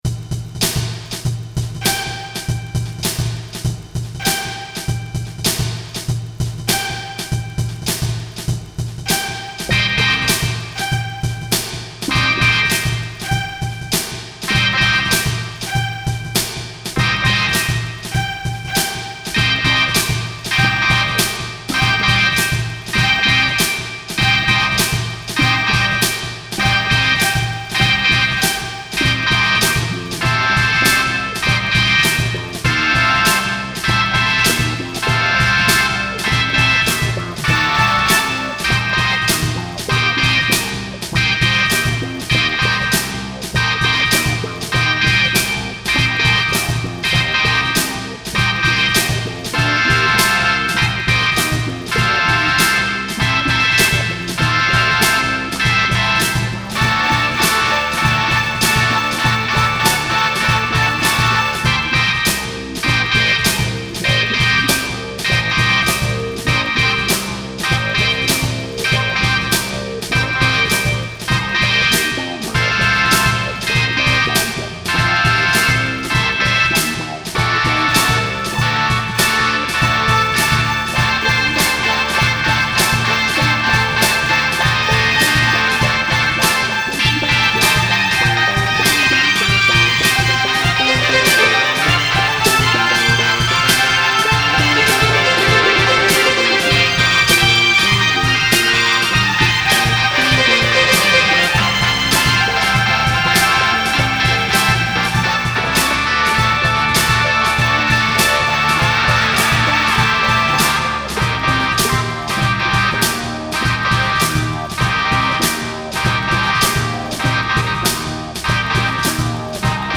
A funky odessy about the space junk that orbits the earth.